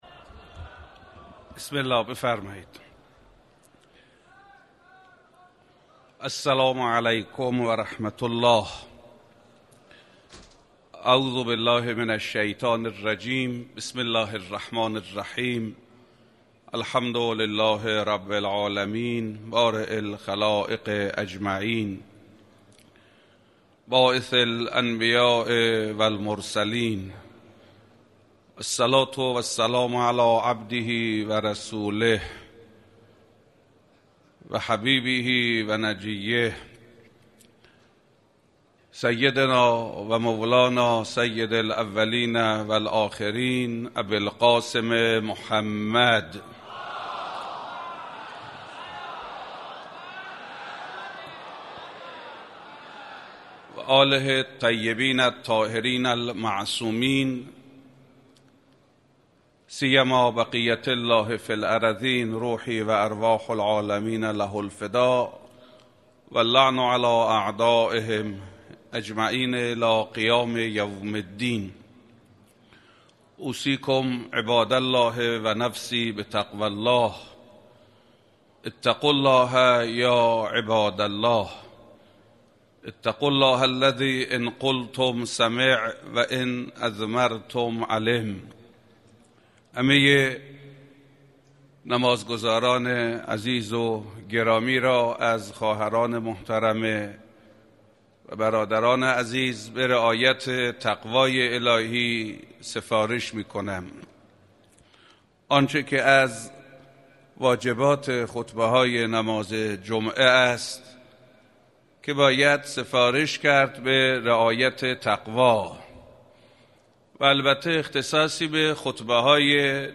نمازجمعه2 بهمن 94 به امامت آیت اله حسن زاده امام جمعه موقت اهواز در مصلای مهدیه امام خمینی(ره)اهواز برگزار شد.